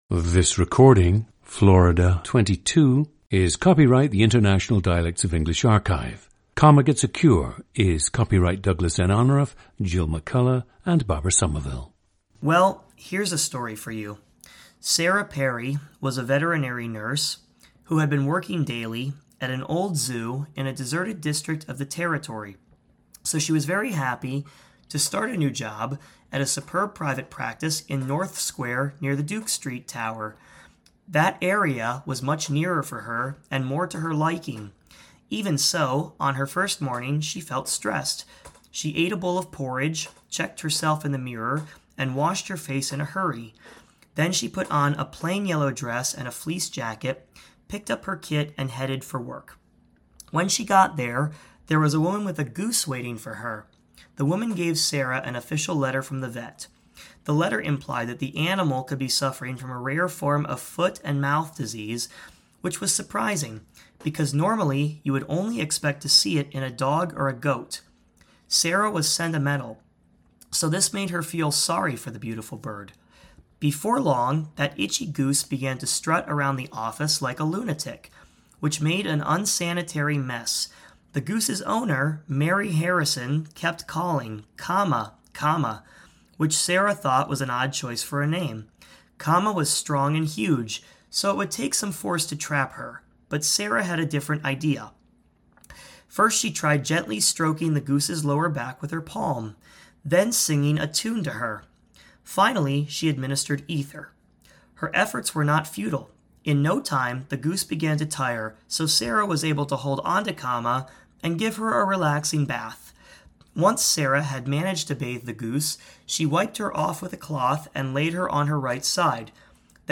GENDER: male
The subject has mostly retained his native Florida dialect, with words such as “horrible,” “Orange,” and “Florida” being pronounced differently than the expected pronunciations in the region where the speaker currently resides.
• Recordings of accent/dialect speakers from the region you select.
The recordings average four minutes in length and feature both the reading of one of two standard passages, and some unscripted speech.